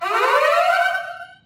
alarm.mp3